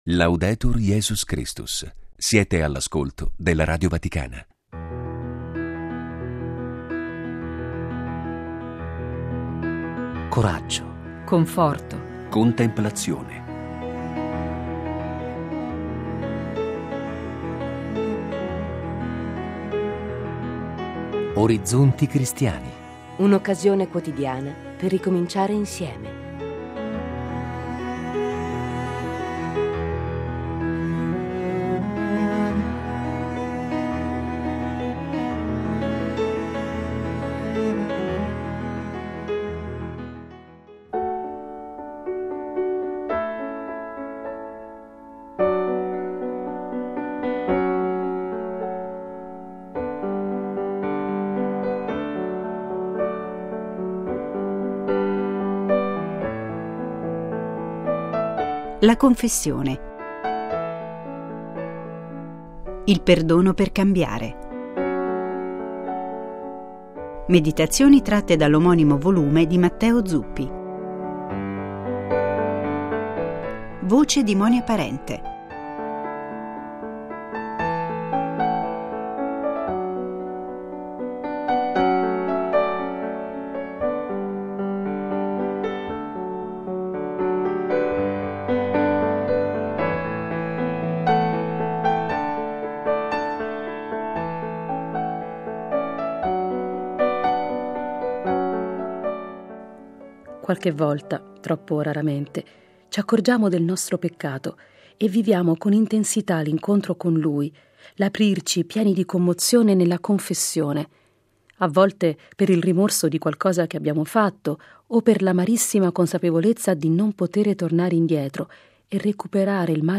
La confessione: il perdono per cambiare. Meditazioni di Matteo Zuppi